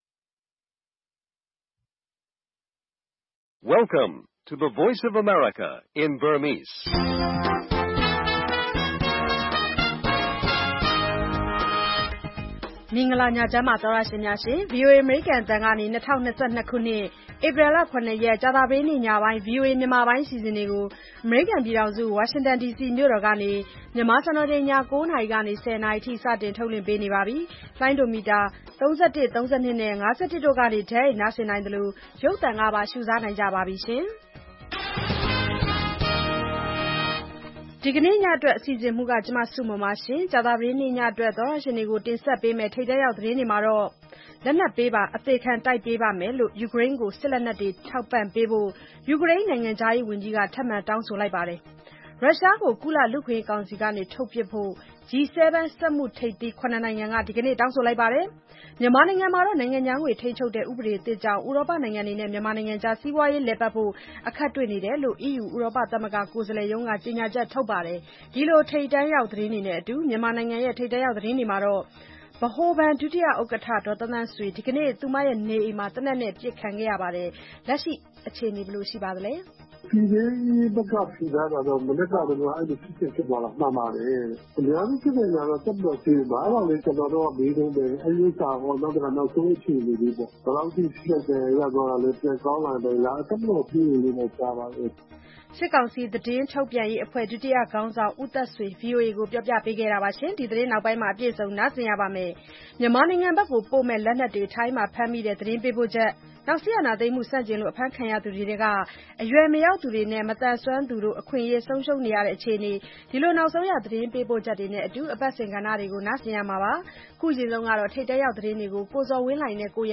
ဗွီအိုအေ ကြာသပတေးည ၉း၀၀-၁၀း၀၀ နာရီ ရေဒီယို/ရုပ်သံလွှင့်အစီအစဉ်